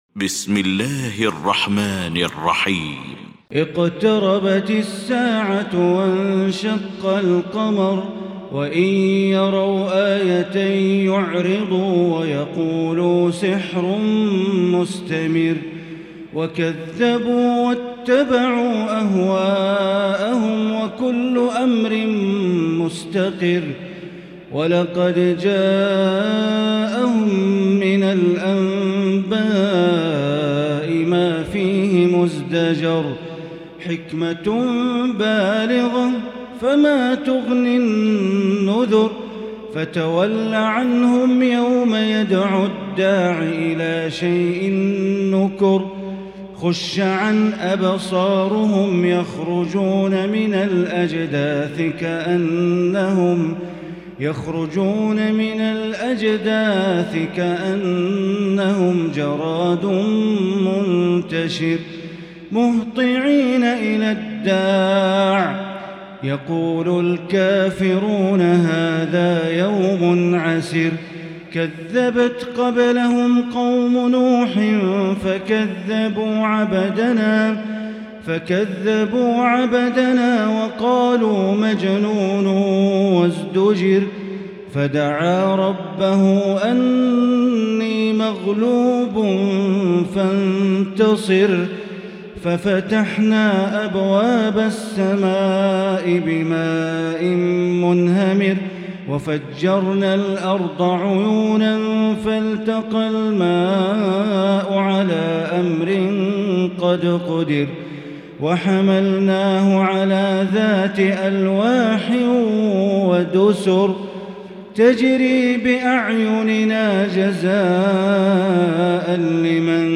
المكان: المسجد الحرام الشيخ: معالي الشيخ أ.د. بندر بليلة معالي الشيخ أ.د. بندر بليلة القمر